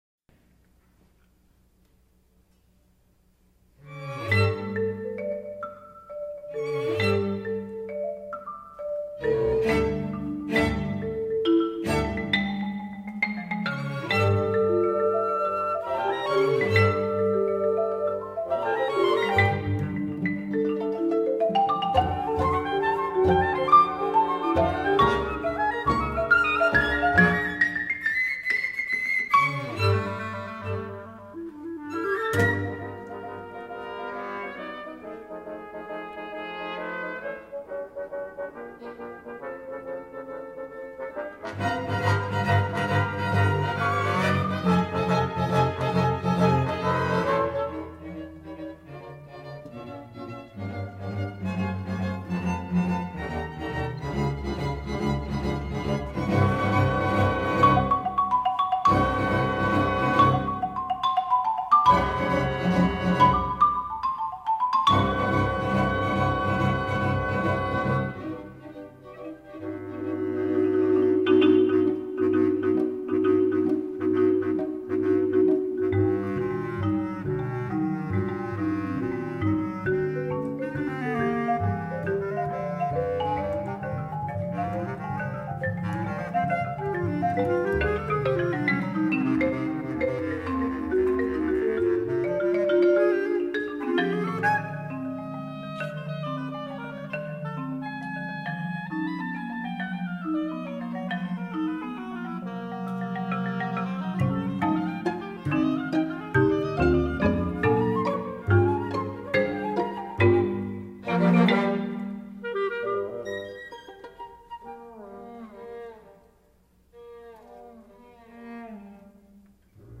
solo marimba & chamber orchestra
(fl, ob, cl, bn, hn, tp, tb, strings)